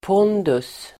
Uttal: [p'ån:dus]